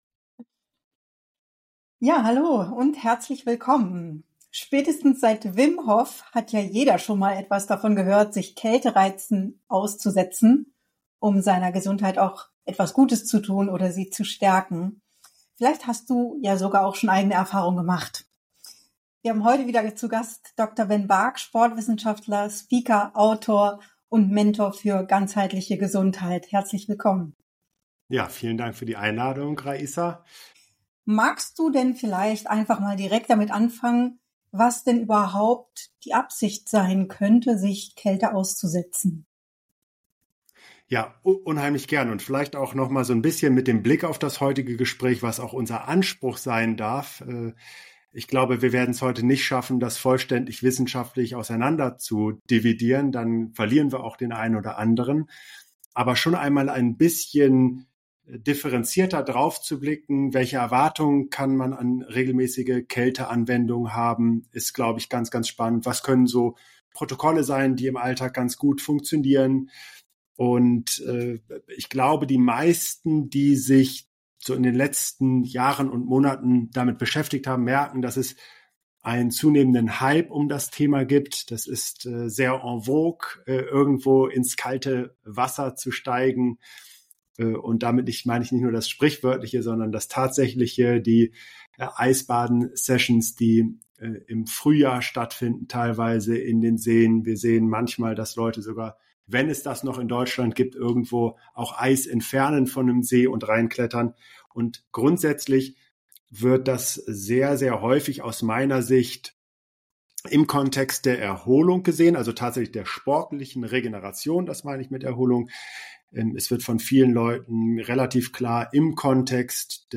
In diesem Gespräch wird der Einfluss von Kälteanwendungen auf die psychische und physische Gesundheit erörtert.